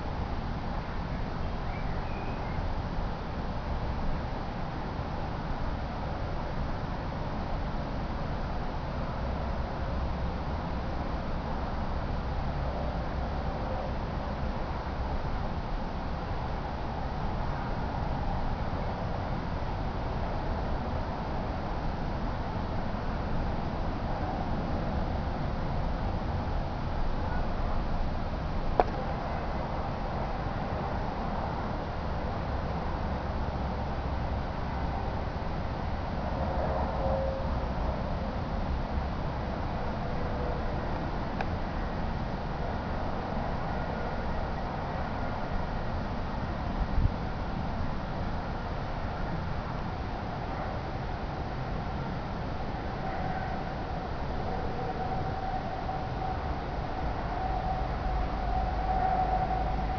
Verkehrslärm
Über eine Entfernung von rund 400 Metern ist der Lärm der Autobahn A1 zu hören, obwohl es einen Lärmschutzwall gibt.
Zwischen den Häusern und der Straße liegt eine Wiesenfläche und eine Kleingartenanlage. Das Gelände ist flach.
Aufnahmezeit gegen Abend,  Original date/time: 2009:07:29 19:31:58
Abb. 01: Das gleichmäßige Rauschen im Bereich bis etwa 200 Hertz wird manchmal von charakteristischen Reifengeräuschen  etwa ab der 55. Sekunde bei rund 800 Hz übertönt.
Es gibt auch einige wenige Geräusche von der benachbarten Durchfahrtstraße.